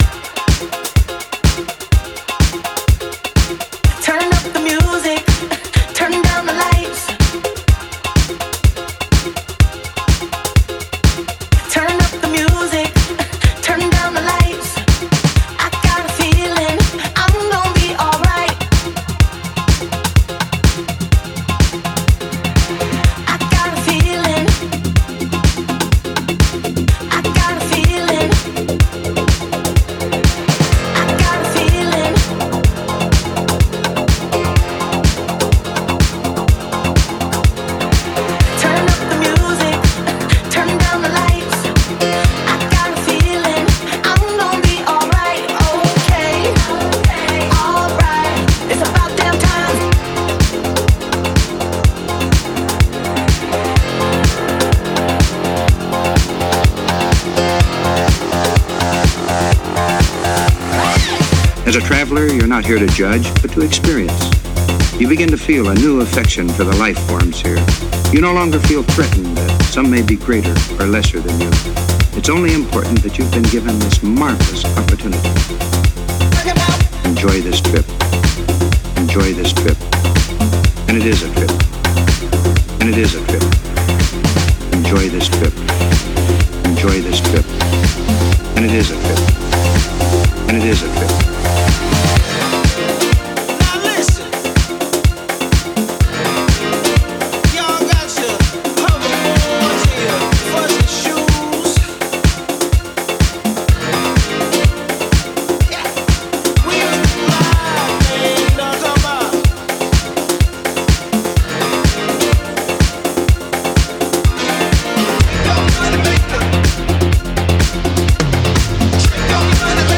This one was actually recorded live!
Another live mixing practice session